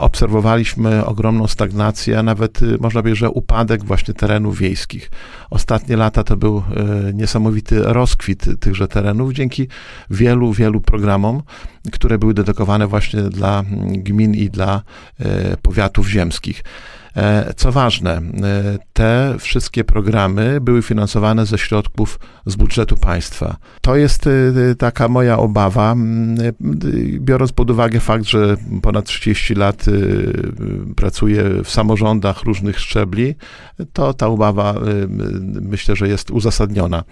Mam duże obawy, że nowy rząd nie będzie tak pozytywnie dostrzegał i wspierał tereny wiejskie – mówił w audycji Gość Dnia Radia Nadzieja starosta powiatu łomżyńskiego, Lech Szabłowski.